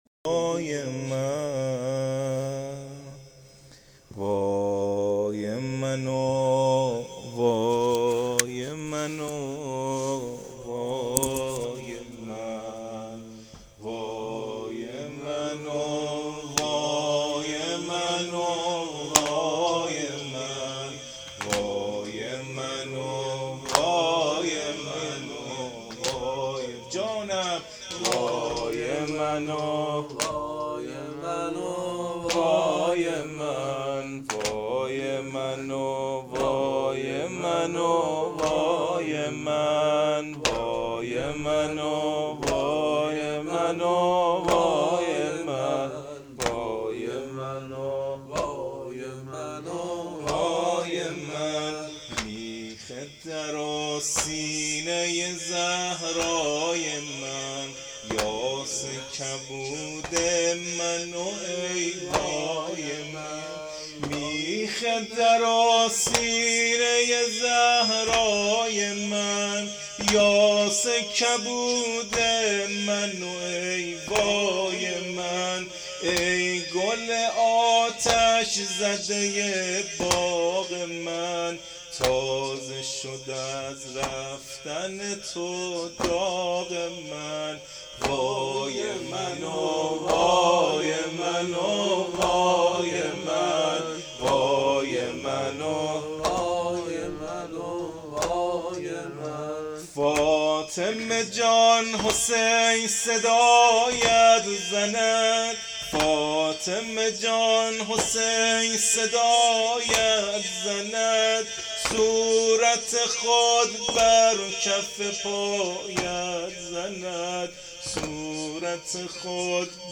نوحه شهادت حضرت زهرا س